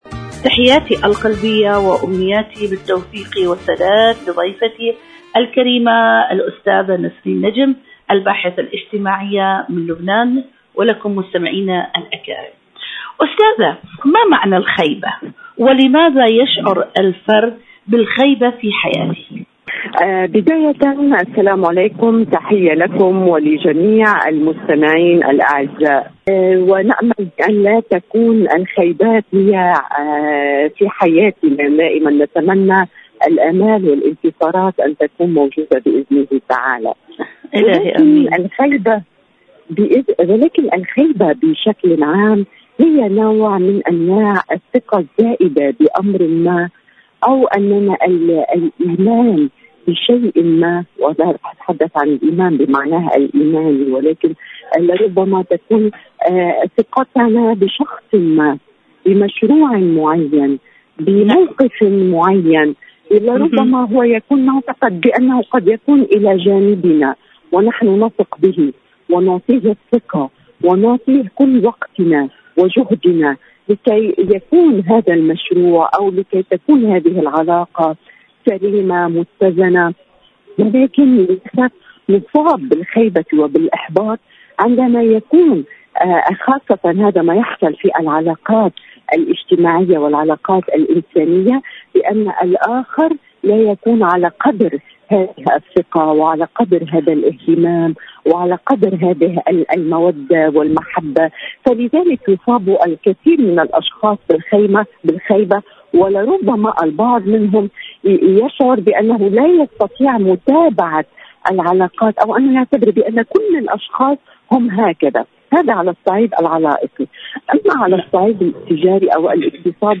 إذاعة طهران- عالم المرأة: مقابلة إذاعية